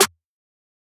MZ Snare [Metro Bape Rare].wav